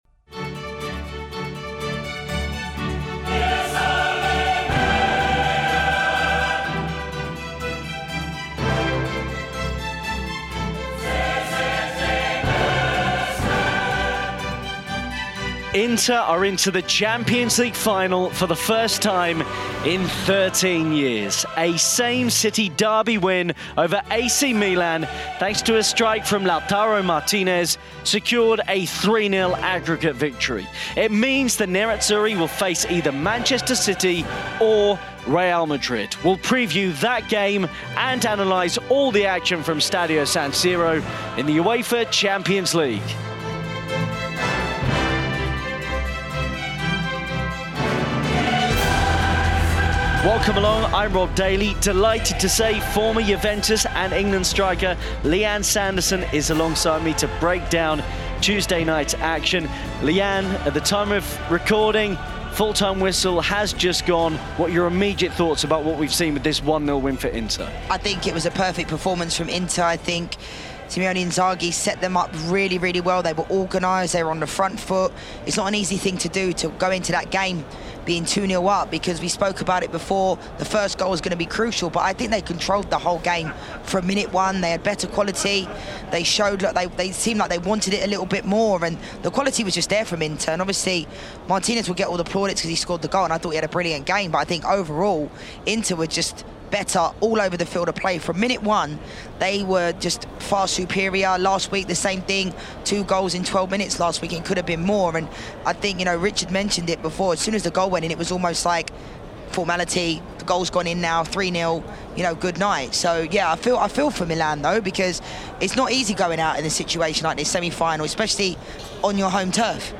Facebook Twitter Headliner Embed Embed Code See more options A clinical second half Lautaro Martinez strike saw Inter seal a comfortable 3-0 aggregate win over neighbours AC Milan and reach a first Champions League Final since 2010. We look back on an enthralling all Italian semi-final and also look ahead to Wednesday’s mouth-watering last four encounter between Manchester City and Real Madrid, hearing from both teams managers, Pep Guardiola and Carlo Ancelotti.